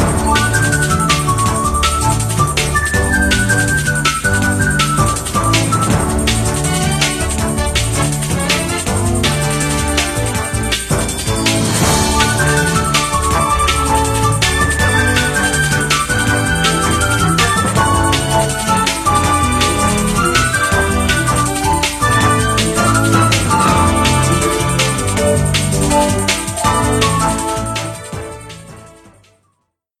Background music
contribs)includes the intro